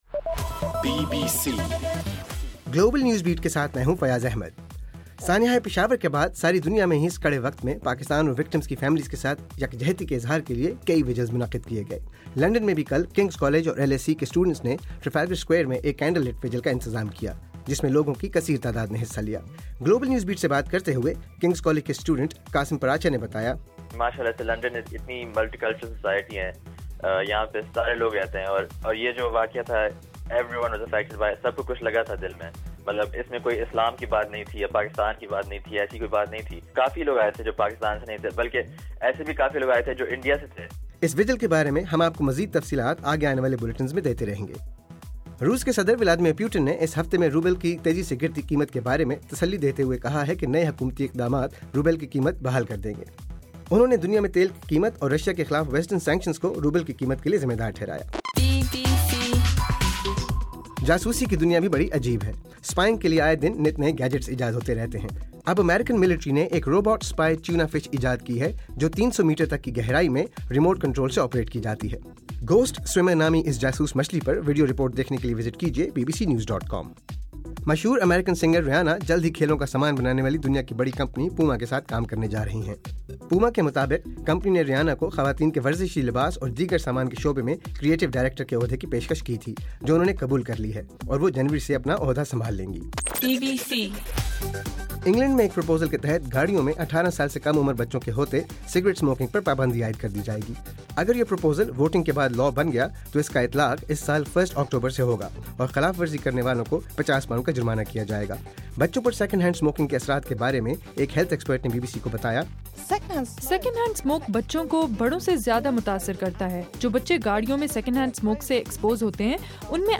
دسمبر 18: رات 8 بجے کا گلوبل نیوز بیٹ بُلیٹن